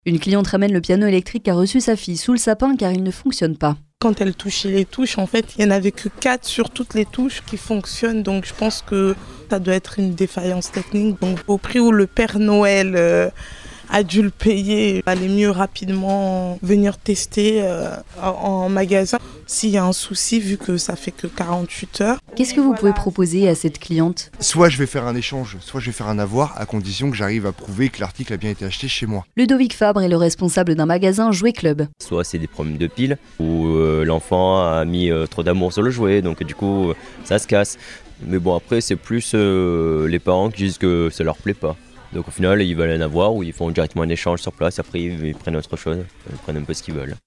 Nice Radio est allée vérifier en magasin au Jouéclub de Nice Notre-Dame.